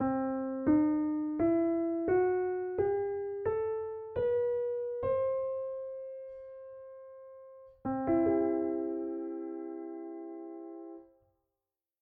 PorcupineOtonalMajor_15edo.mp3